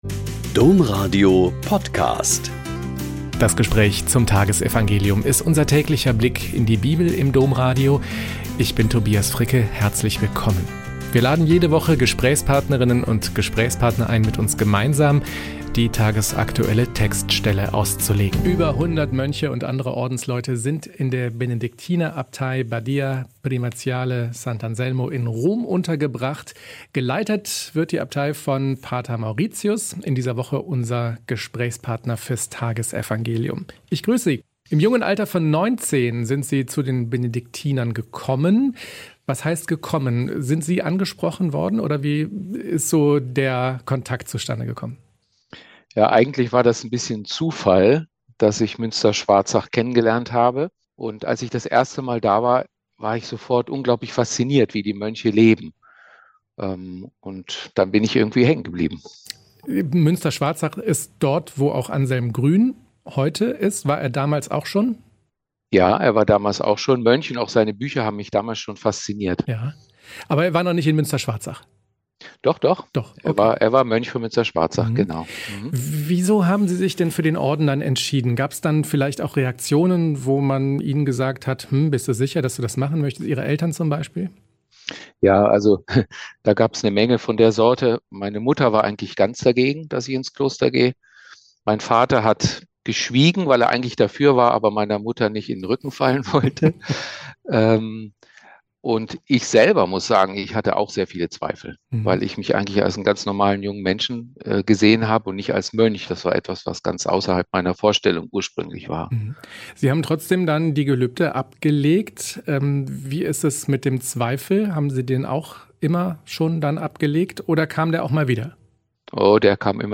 Lk 19,1-10 - Gespräch